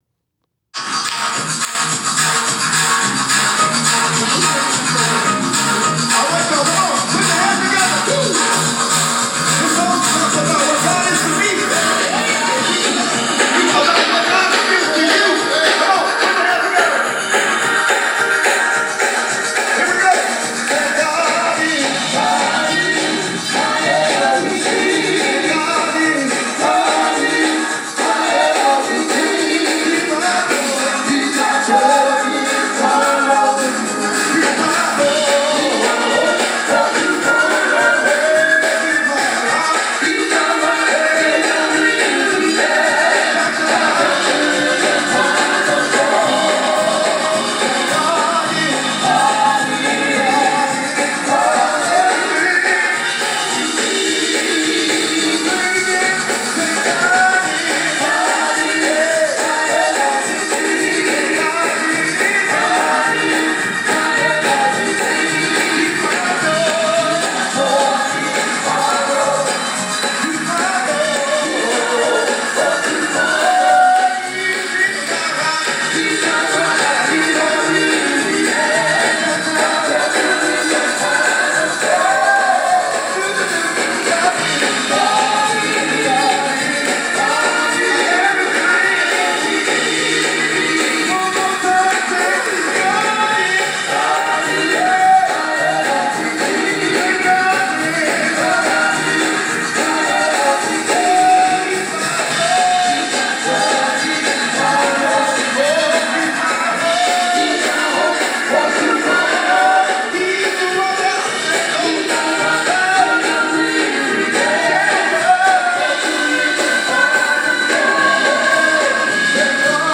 Praise God Is My Everything Lyrics Tenor Alto Soprano
Eb Eb 4/4 107
Gospel